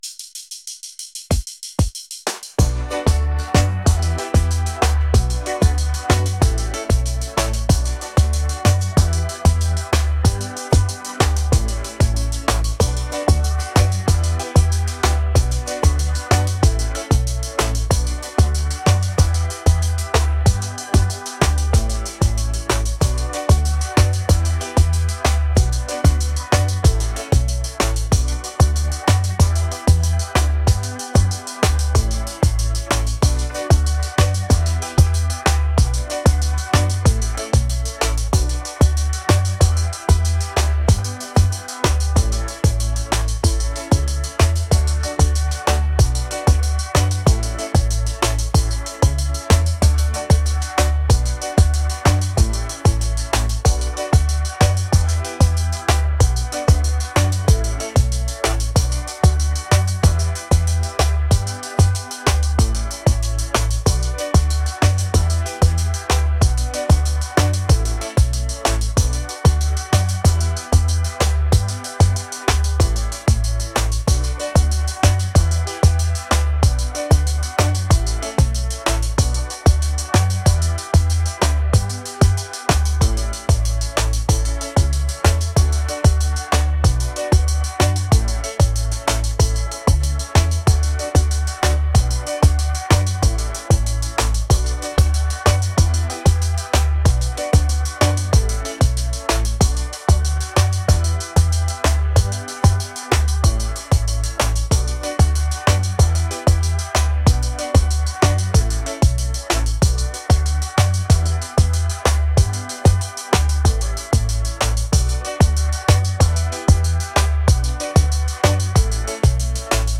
island | fusion | catchy